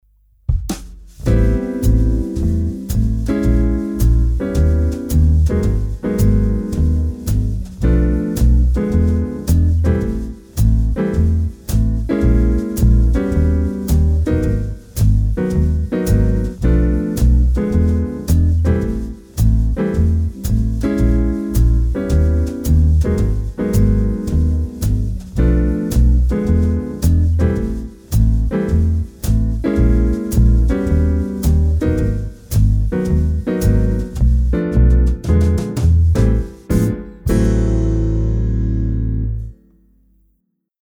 Akkordprogression med modulation og gehørsimprovisation:
Lyt efter bassen, der ofte spiller grundtonen.
C instrument (demo)